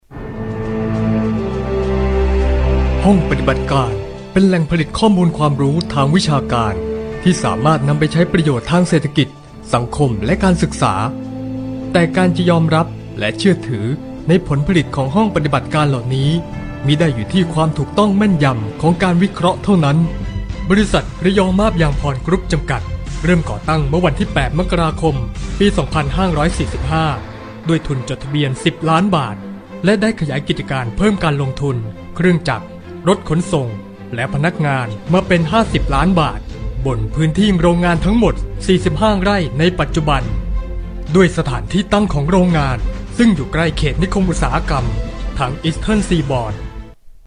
泰语中年大气浑厚磁性 、沉稳 、娓娓道来 、男专题片 、宣传片 、纪录片 、广告 、飞碟说/MG 、课件PPT 、工程介绍 、绘本故事 、动漫动画游戏影视 、100元/百单词男泰语05 泰语男声 干音 大气浑厚磁性|沉稳|娓娓道来
男泰语05 泰语男声 企业宣传片 大气浑厚磁性|沉稳|娓娓道来